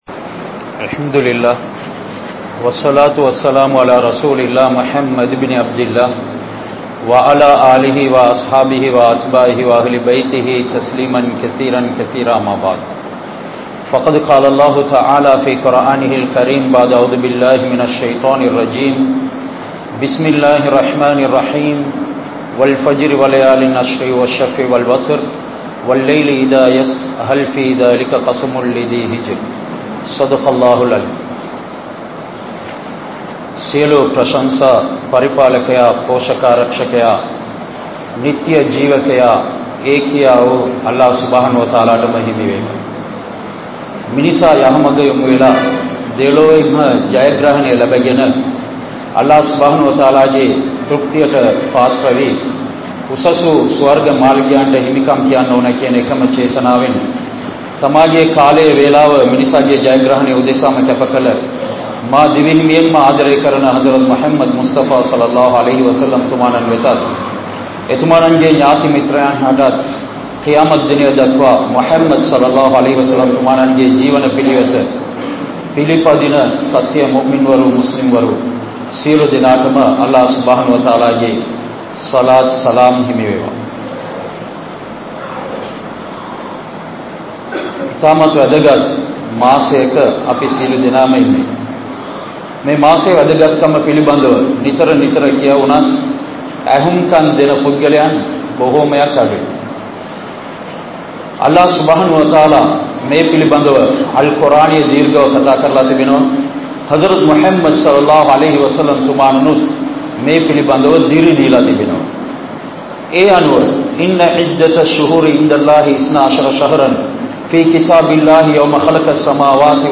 Ulhiya (உழ்ஹிய்யா) | Audio Bayans | All Ceylon Muslim Youth Community | Addalaichenai
Orugodawattha, Humaidiya Jumua Masjidh